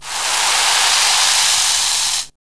2506R FIZZFX.wav